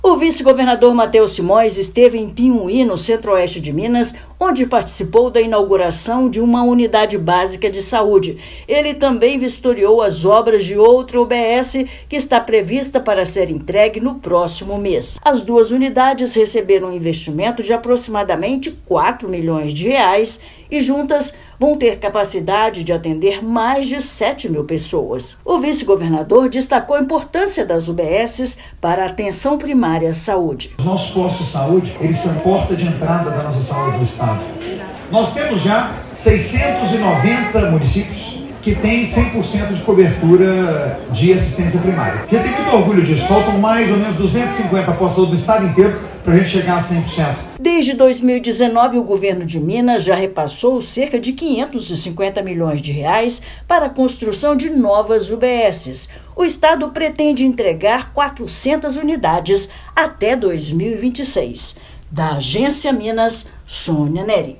Vice-governador também vistoria UBS que será inaugurada em 2026; as duas estruturas atenderão mais de 7 mil pessoas. Ouça matéria de rádio.